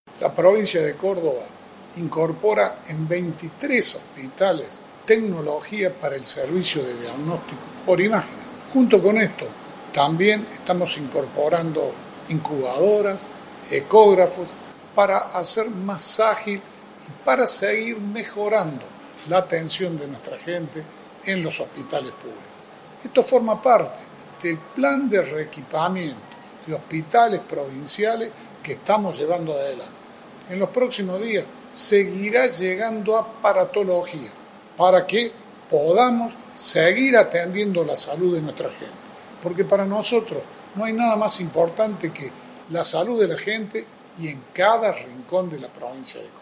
La palabra del gobernador, Juan Schiaretti
Schiaretti-equipamiento-medico.mp3